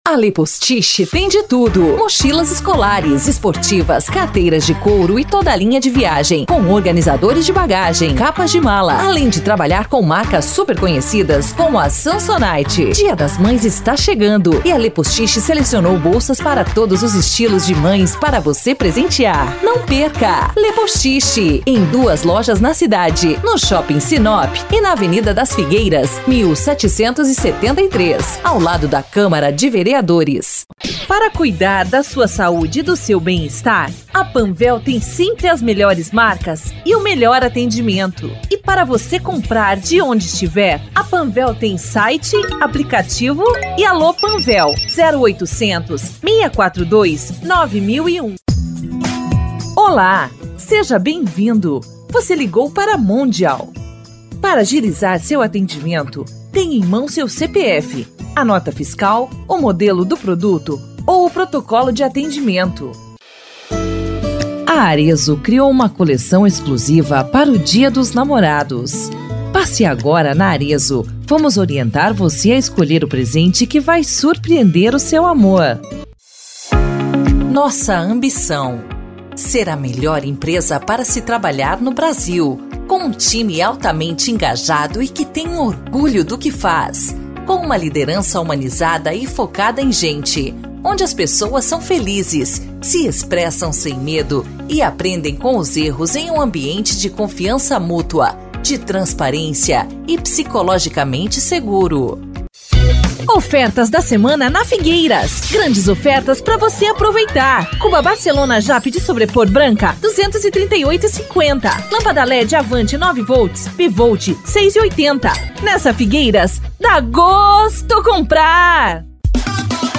Spot Comercial
Vinhetas
Estilo(s):
Padrão
Animada